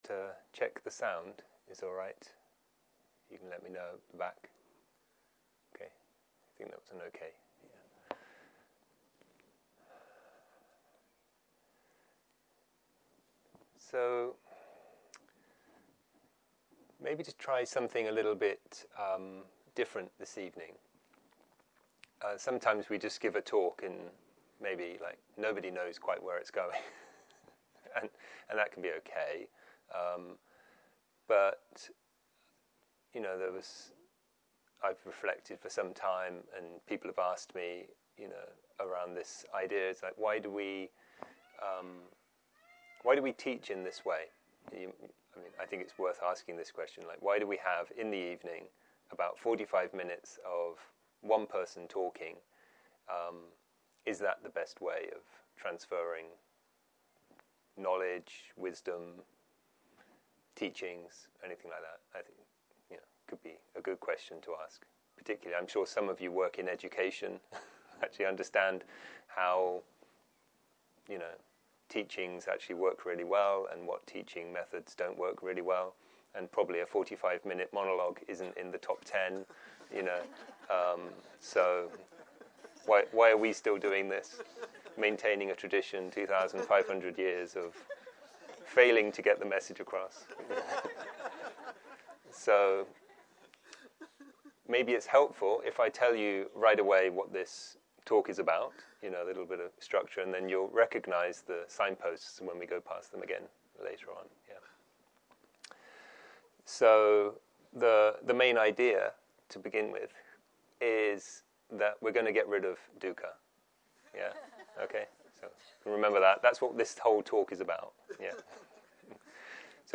ערב - שיחת דהרמה - A fistful of Dukkha